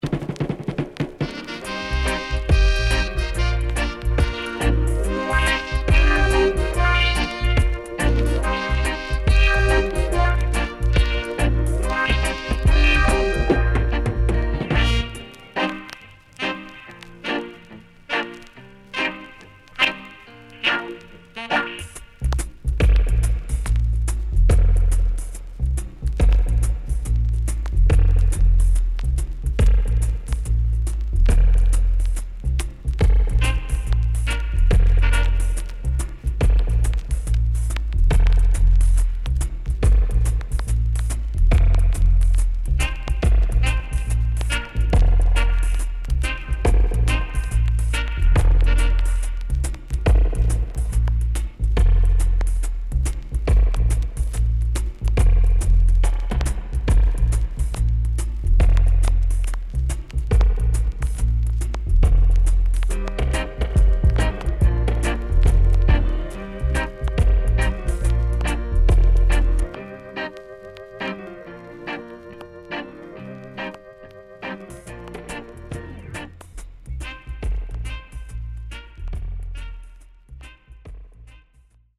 Killer Roots Vocal & Horn
SIDE A:所々チリノイズがあり、少しプチノイズ入ります。